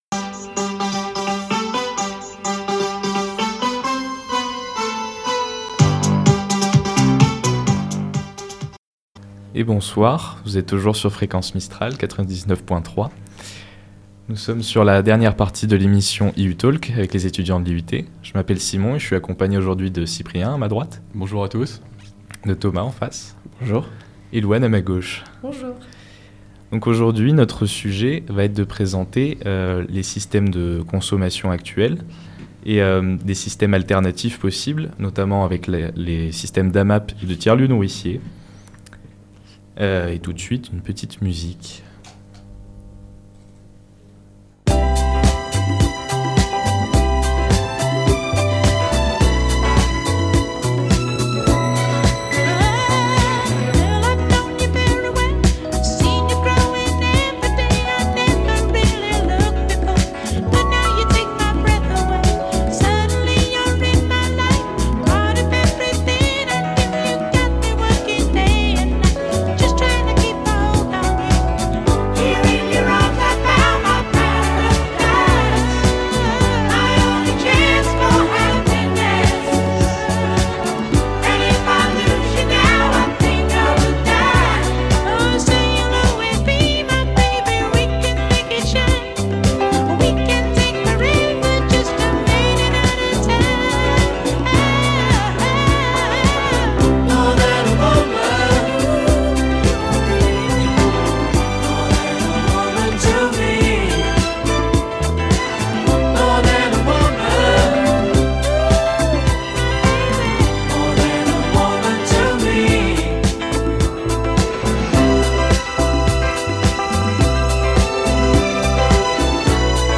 Une émission réalisée entièrement par des étudiantes, et étudiants de l'IUT d'Aix-Marseille Site de Digne-les-Bains saison 2023-2024, en 2ème année d'agronomie.